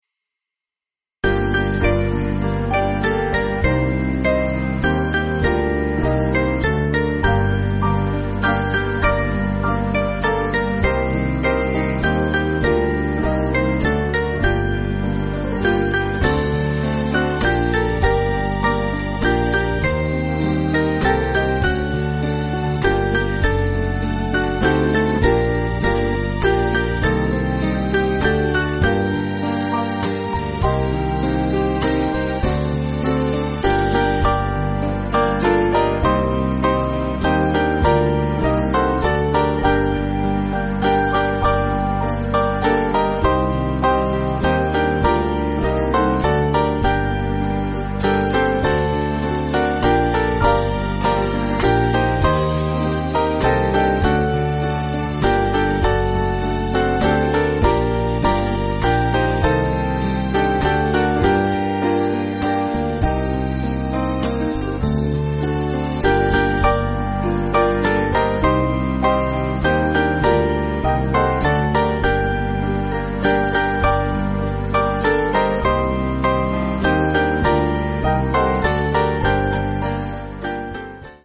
Small Band
(CM)   3/Gm